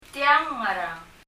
[tjəŋ ŋər əŋ] あるいは